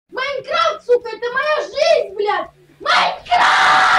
голосовые
злые
матерные
крик